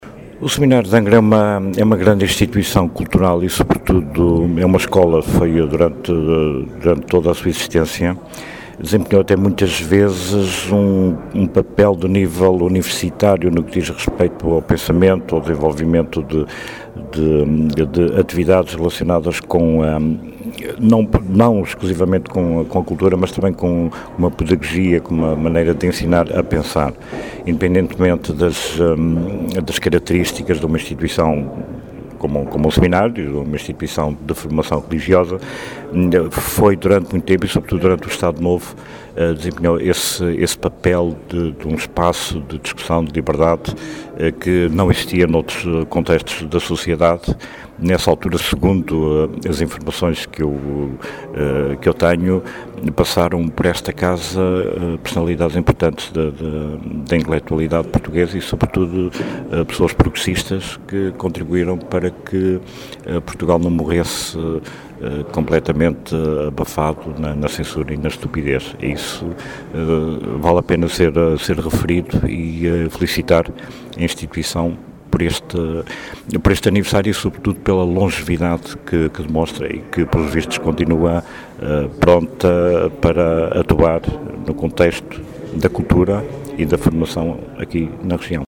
A afirmação é do Secretário Regional da Educação, Cultura e Ciência, à margem de um ato comemorativo do tricinquentenário daquela instituição, em que participou na noite desta sexta-feira, no salão nobre da Câmara Municipal de Angra do Heroísmo.